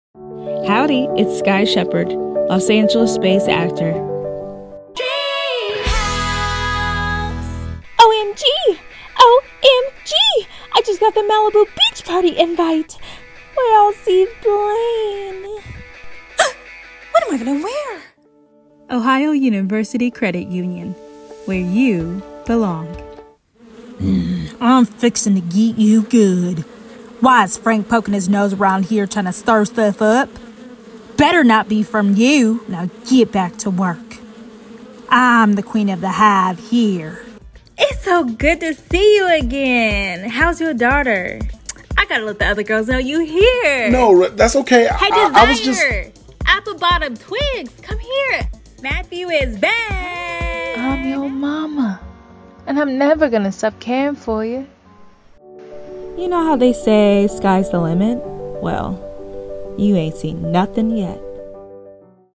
VO Reel